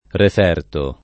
vai all'elenco alfabetico delle voci ingrandisci il carattere 100% rimpicciolisci il carattere stampa invia tramite posta elettronica codividi su Facebook referto [ ref $ rto ] s. m. («relazione») — diverso da reperto